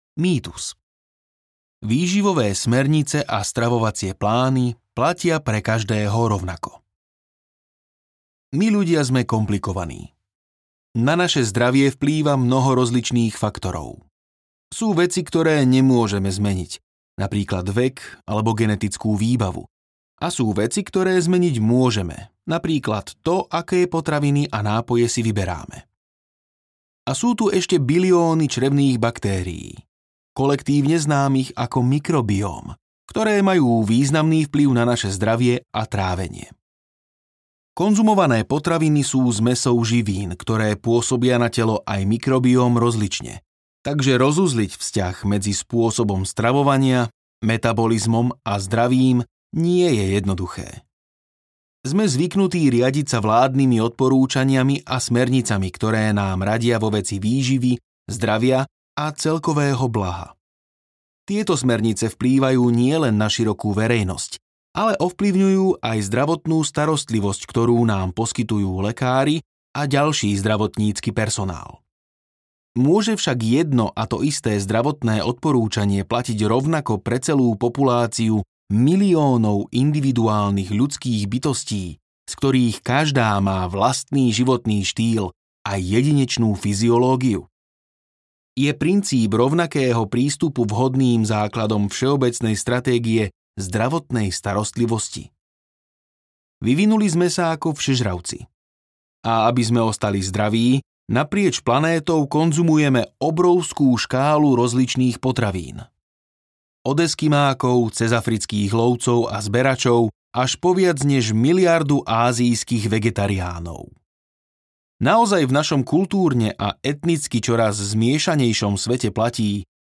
Audiokniha Čo naozaj jeme?